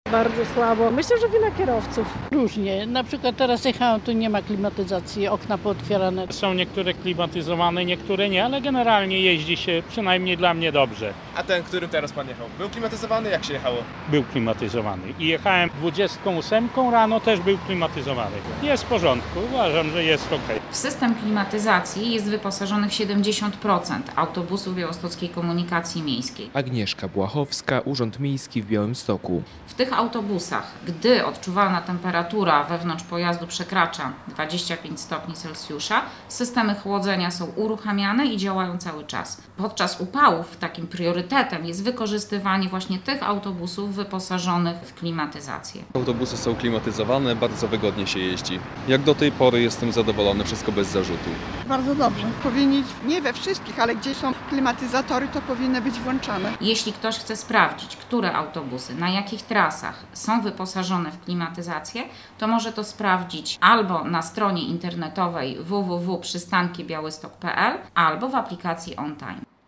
Białostoczanie przyznają, że klimatyzacja w większości autobusów działa poprawnie - relacja